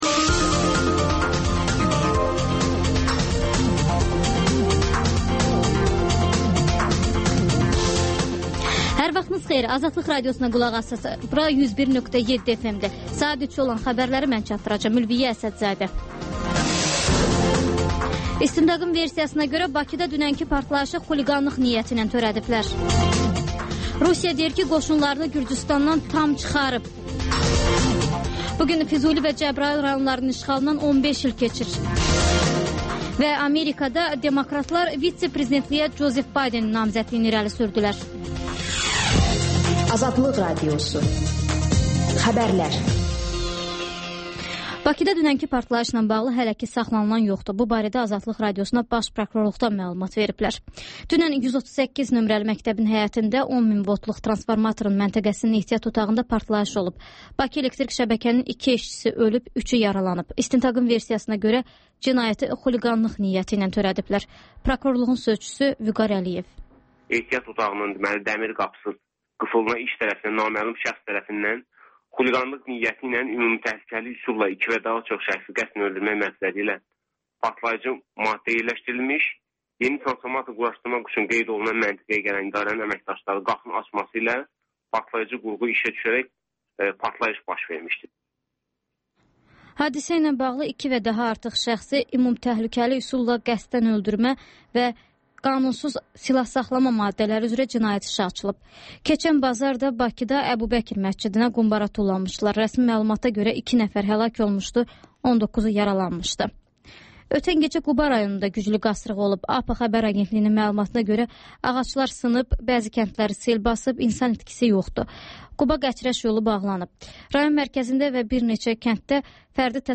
Xəbərlər, QAYNAR XƏTT: Dinləyici şikayətləri əsasında hazırlanmış veriliş, sonda MÜXBİR SAATI